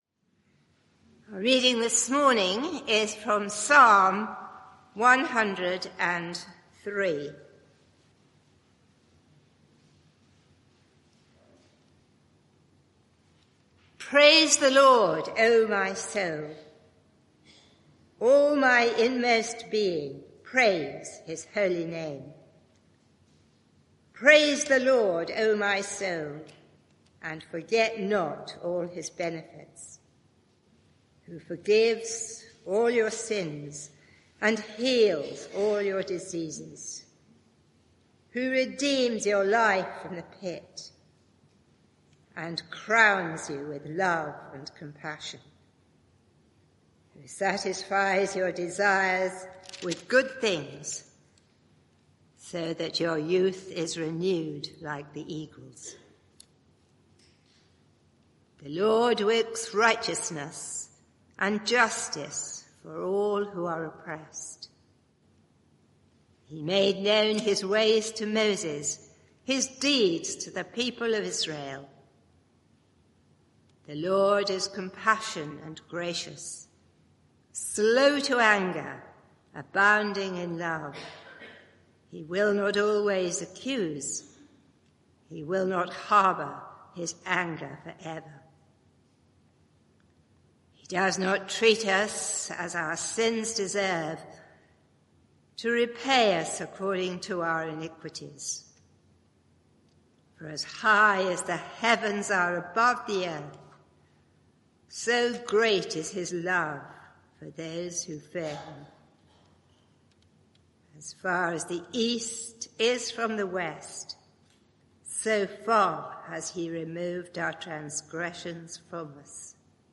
Media for 11am Service on Sun 04th May 2025 11:00 Speaker
Sermon (audio) Search the media library There are recordings here going back several years.